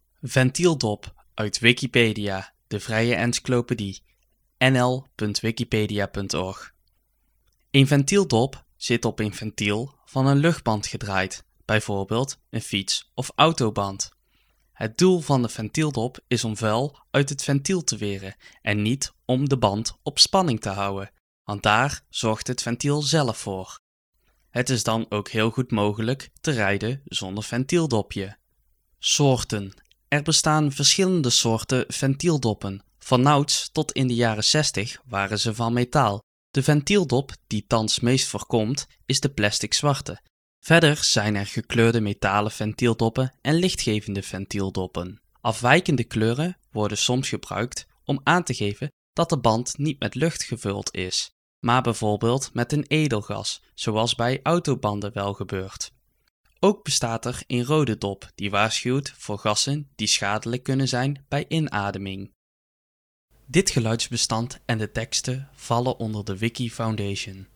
Nederlands: Ventieldop Dutch Spoken article